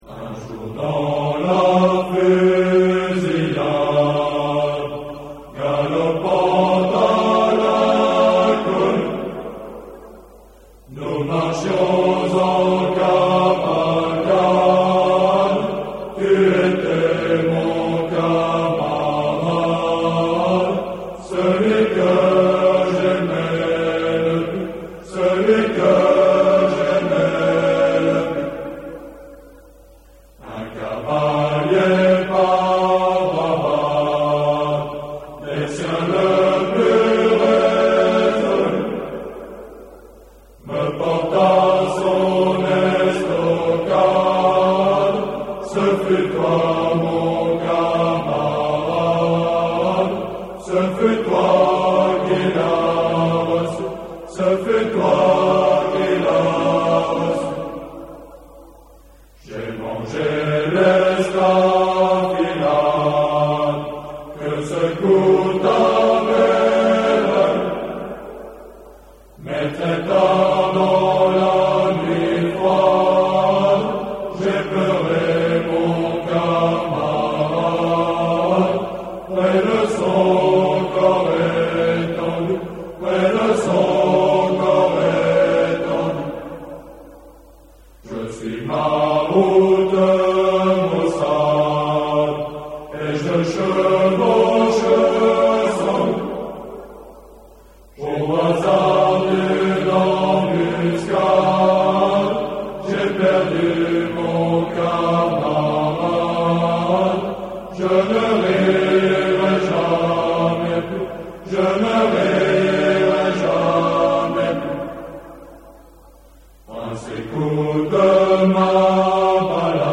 Chants scouts